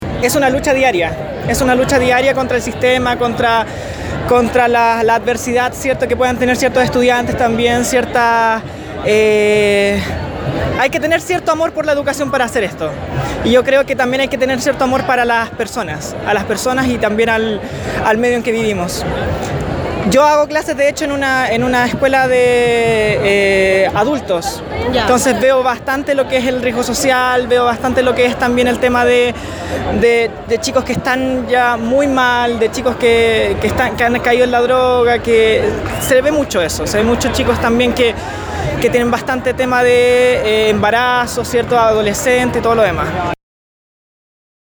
Desde la Izquierda Diario conversamos con varios docentes a lo largo de la marcha sobre lo que viven día a día en las salas de clase, siendo parte de la diversidad sexual.